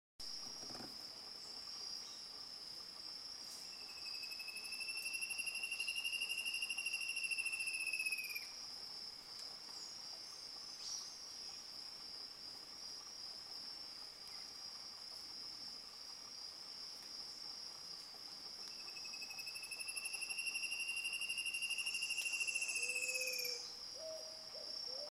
Chocão-carijó (Hypoedaleus guttatus)
Nome em Inglês: Spot-backed Antshrike
Fase da vida: Adulto
Detalhada localização: La Misión Lodge
Condição: Selvagem
Certeza: Gravado Vocal